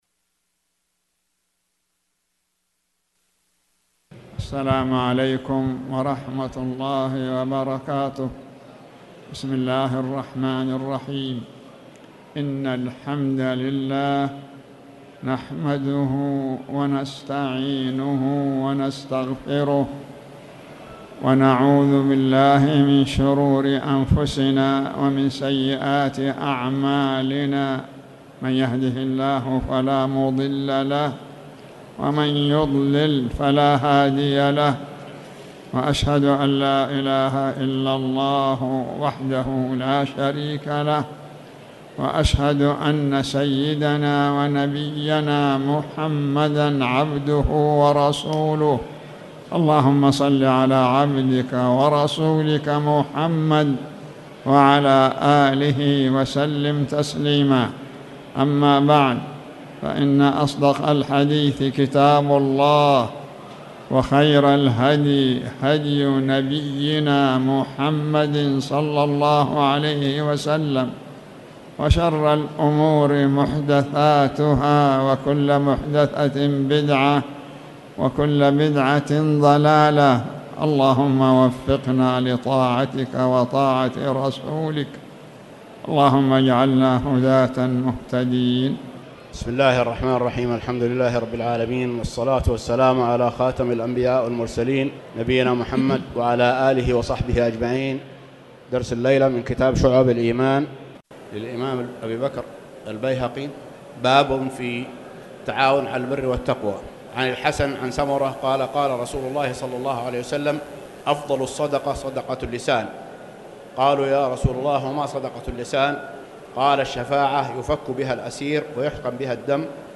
تاريخ النشر ٢٣ صفر ١٤٣٩ هـ المكان: المسجد الحرام الشيخ